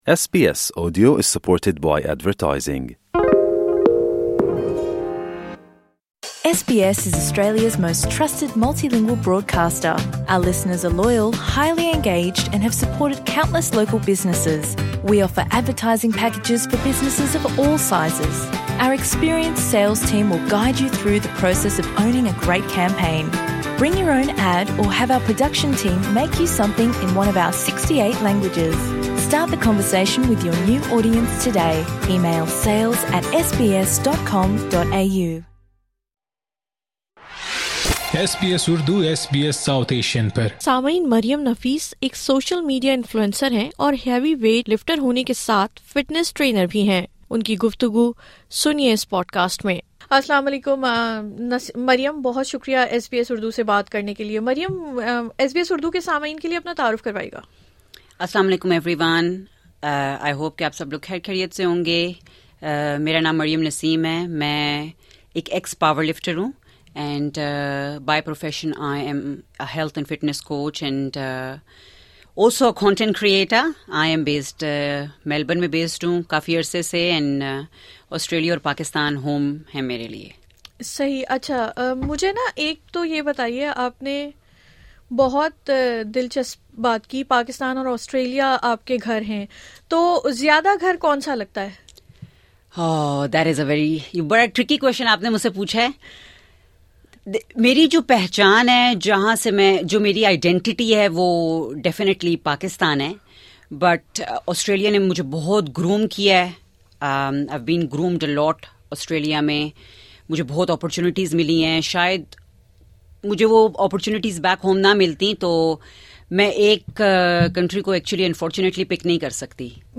ایس بی اسی اردو سے اپنی گفتگو میں ان کا کہنا تھا کہ جس طرح کھانا پینا زندگی کا حصہ ہے اسی طرح ورزش کو بھی روکا یا ختم نہیں کیا جا سکتا ، وقت اور عمر کے ساتھ صحت آپ کی پہلی ترجیح ہونی چاہئے ۔